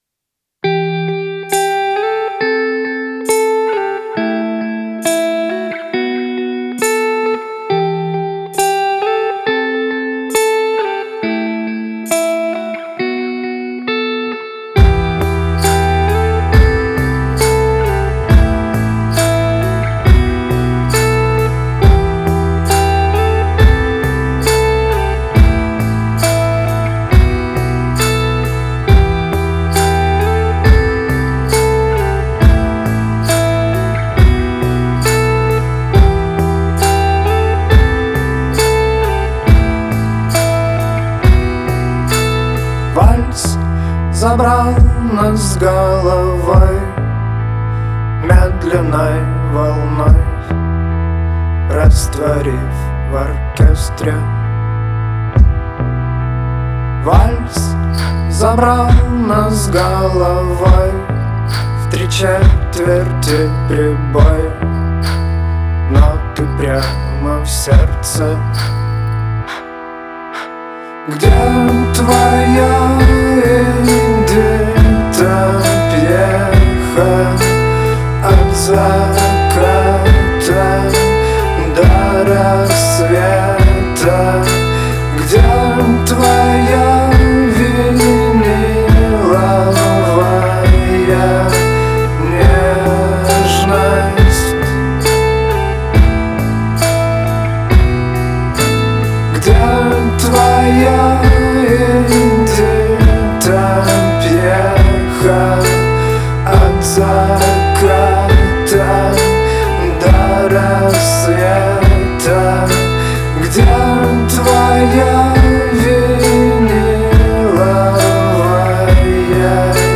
Свидание - Вальс